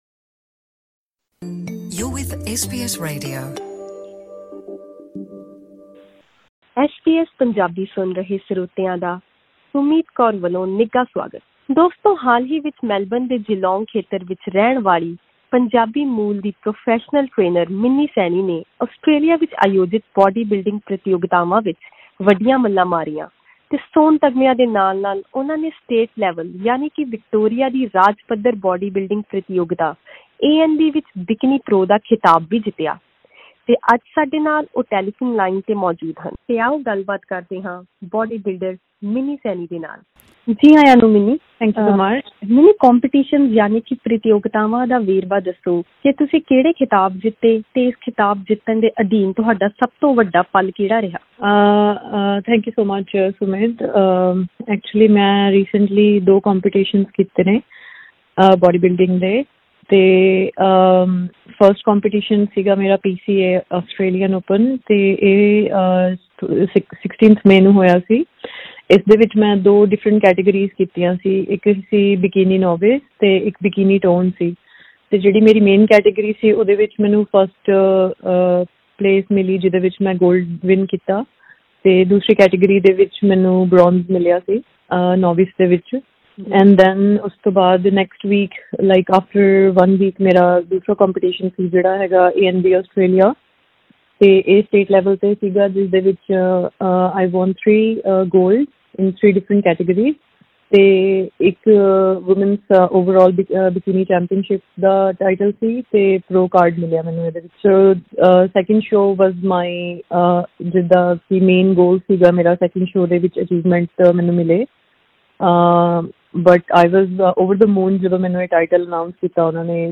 ਇਸ ਇੰਟਰਵਿਊ ਵਿੱਚ ਉਸਨੇ ਬਾਡੀ-ਬਿਲਡਿੰਗ ਦੇ ਸ਼ੁਰੂਆਤੀ ਸਫਰ ਅਤੇ ਫਿੱਟਨੈੱਸ ਟ੍ਰੇਨਿੰਗ ਬਾਰੇ ਅਹਿਮ ਜਾਣਕਾਰੀ ਦਿੱਤੀ।